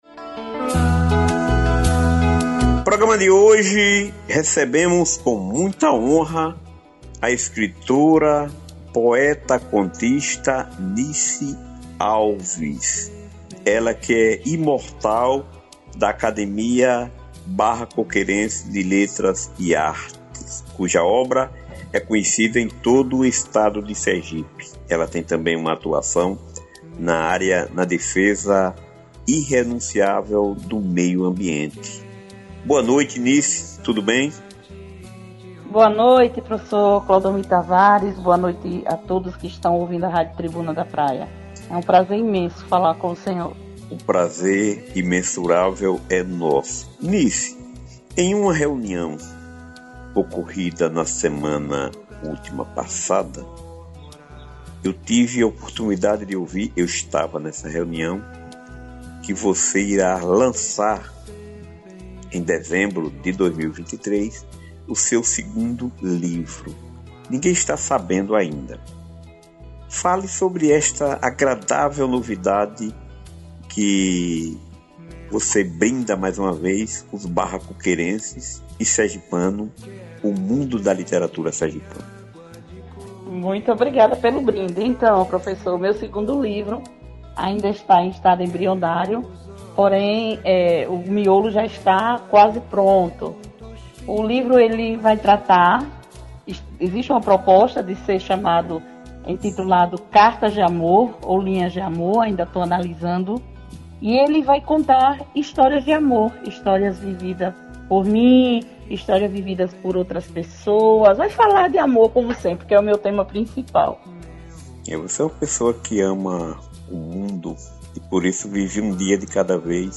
Confira na imtegra o áudio gentilmente cedido pela rádio Tribuna da Praia: